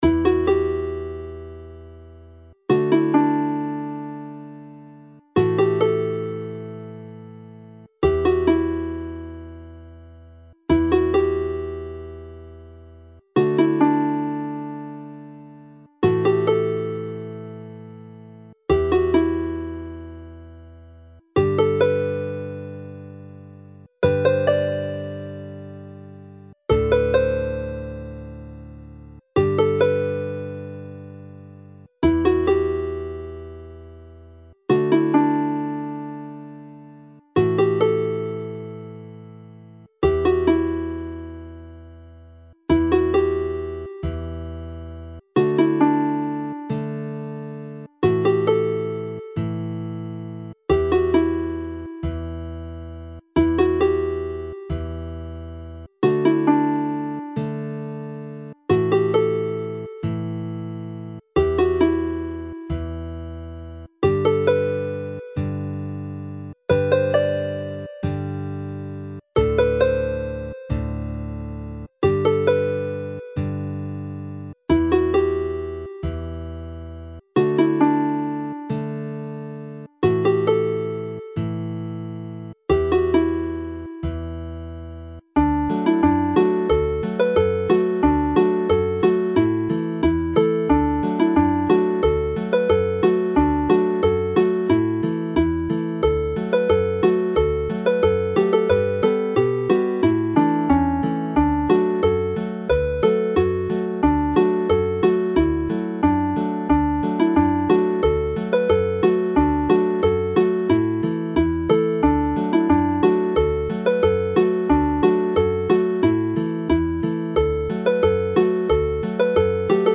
Play the set slowly
The haunting air Lorient which starts this set can be heard around the streets of Lorient during the festival as a tuning-up routine used in warming up by the pipers in Breton Bagapipe bands.
Hela'r Geinach (Hunting the Hare) is clearly another pipe tune which is more lively whilst Aden y Frân Ddu (the Black Crow's Wing) is livelier still.